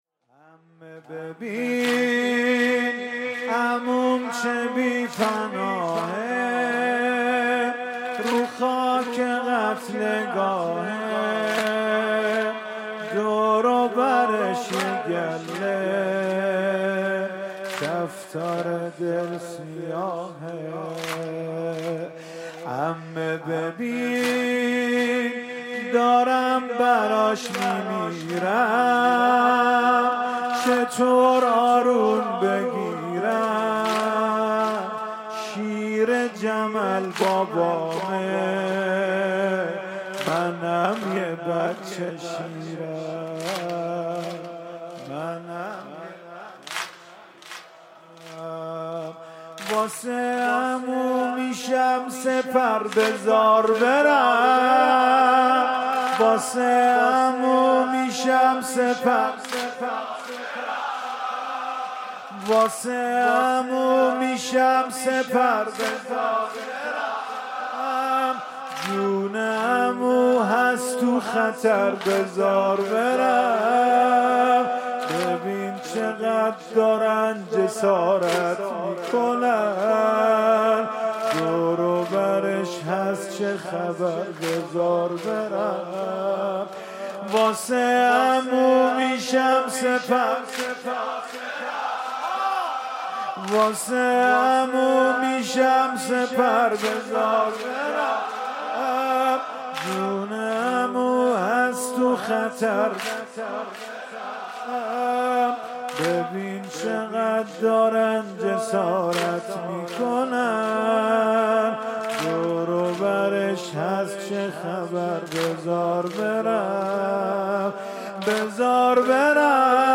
شب پنجم محرم 96 - مصباح الهدی - واحد - عمه ببین عموم چه بی پناهه